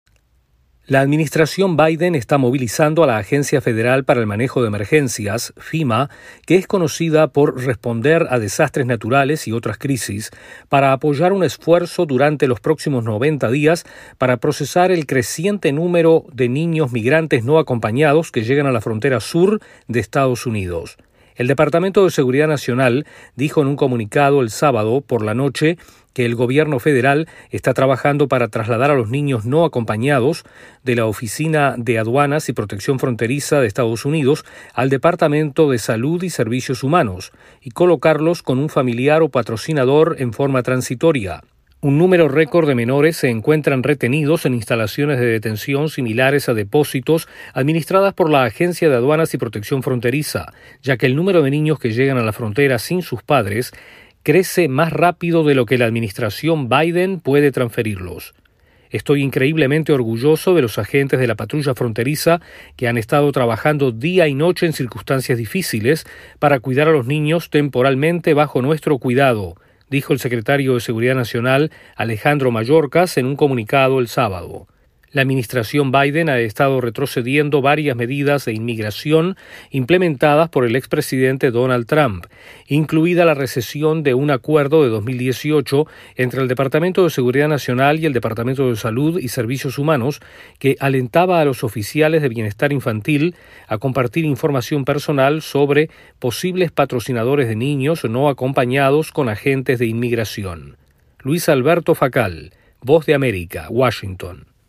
El presidente Biden ordena a la agencia de manejo de emergencias que ayude con el aumento de niños migrantes en la frontera sur. Informa